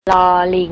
ลอ-ลิง
lor ling
long